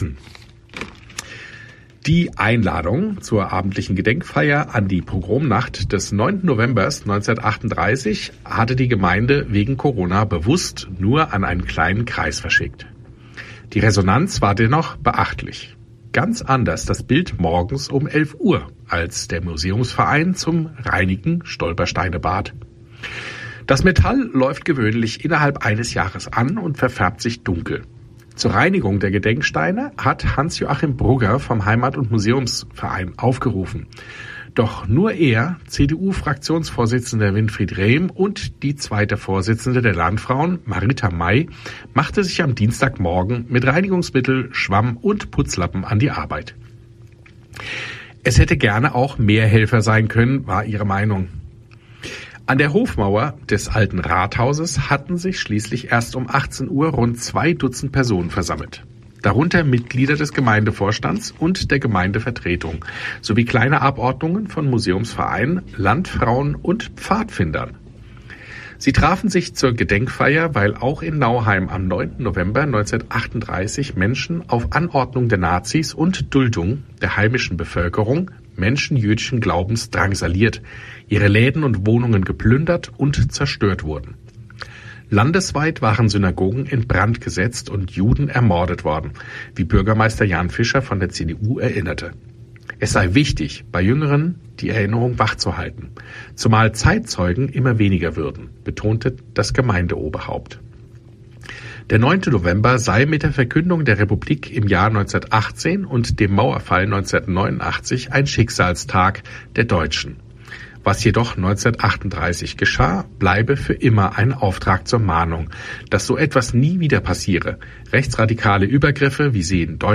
Bürgermeister Jan Fischer erinnerte in seiner Ansprache an den geschichtsträchtigen Tag.